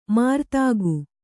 ♪ mārtāgu